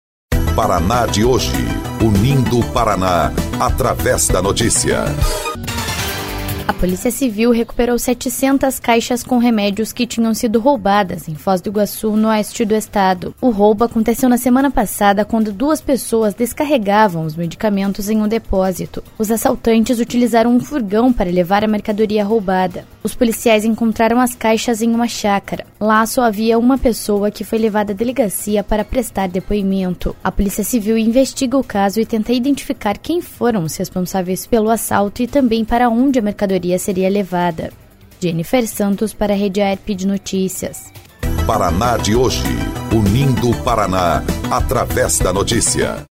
22.05 – BOLETIM – Polícia recupera medicamentos roubados em Foz do Iguaçu